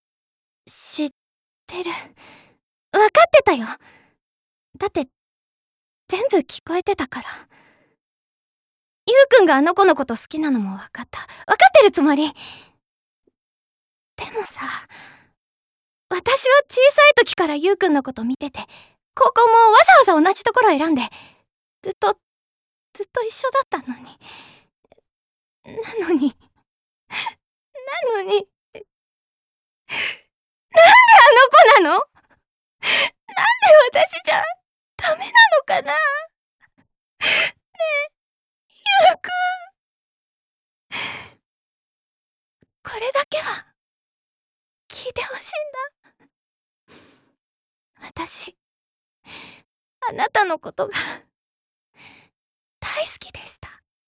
ボイスサンプル
女子高生